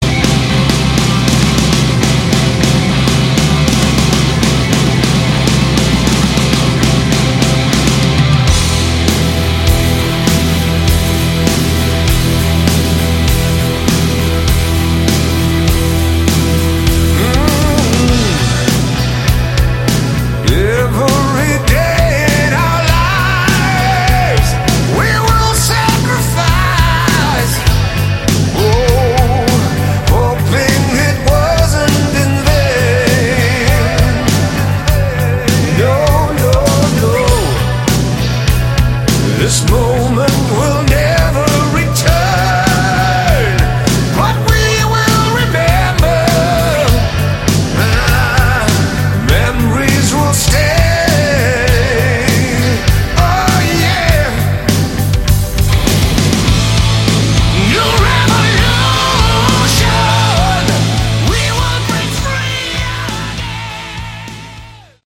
Category: Hard Rock
vocals
guitars, bass, keyboards
drums